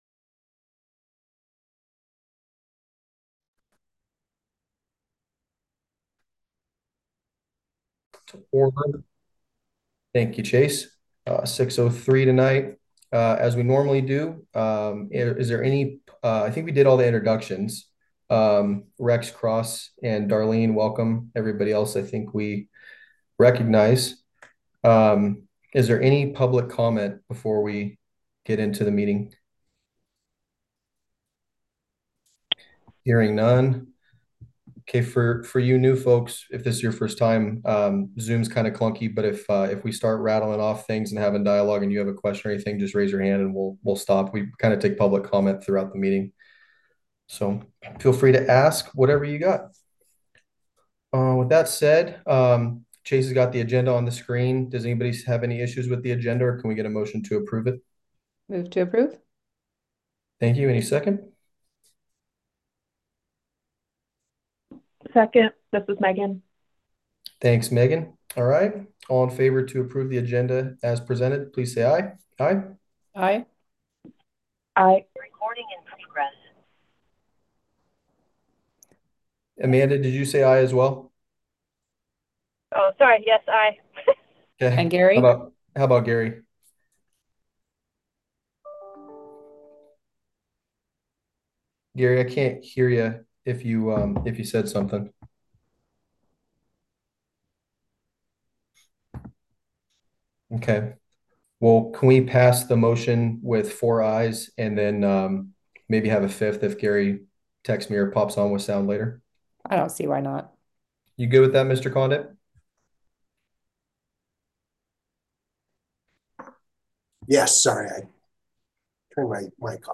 Board Monthly Meeting